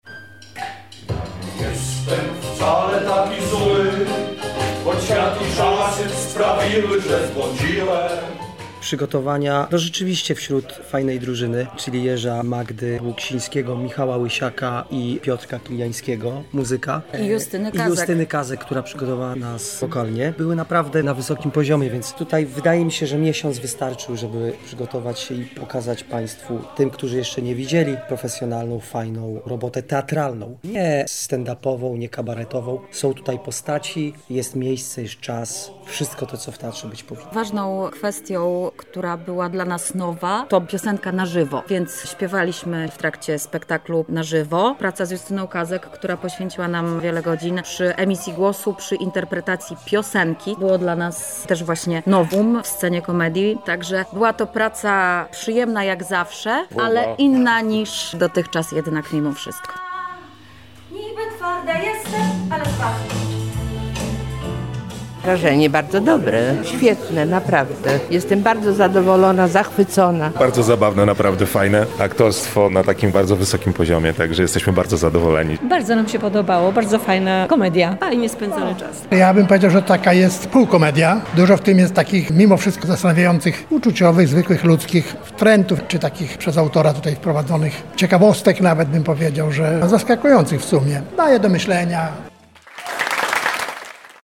O wrażenia po spektaklu zapytaliśmy również widzów:
Skazani na klawo, relacja